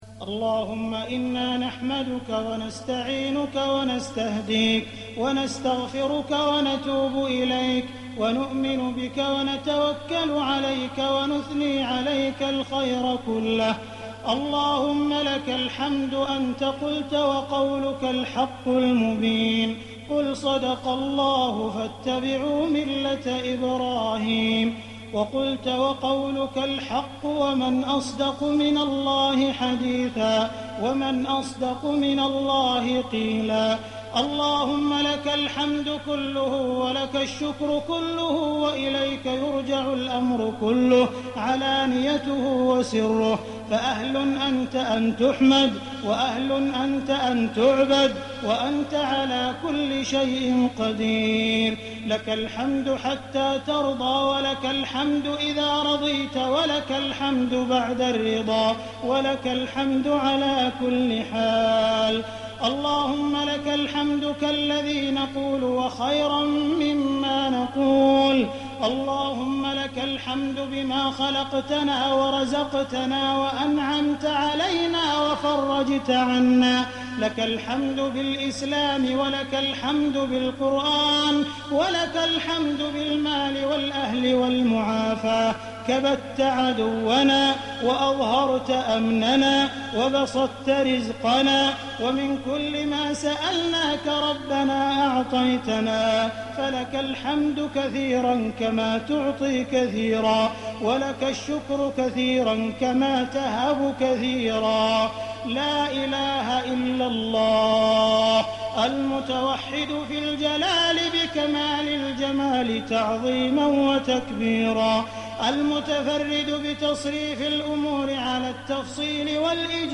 دعاء ختم القرآن ليلة 29 رمضان 1419هـ | Dua for the night of 29 Ramadan 1419H > تراويح الحرم المكي عام 1419 🕋 > التراويح - تلاوات الحرمين